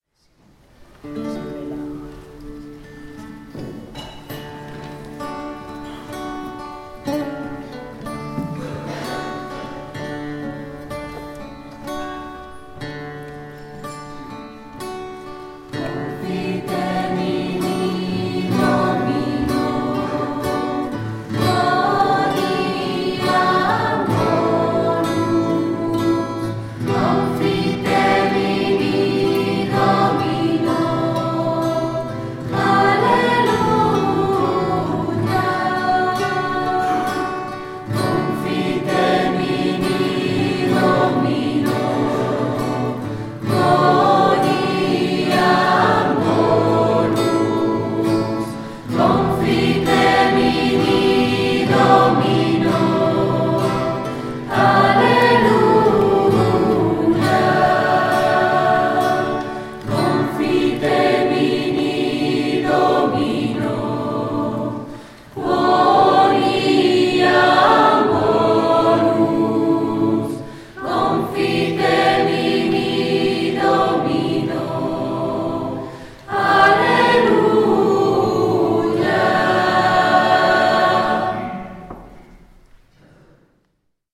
CANTO: